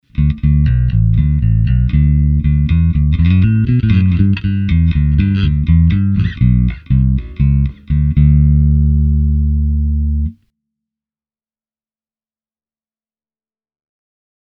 Tältä basso kuulostaa soitettuna SansAmp Bass Driver DI:n läpi:
molemmat mikrofonit – sormisoitto
lakland-skyline-44-60-both-pus-finger.mp3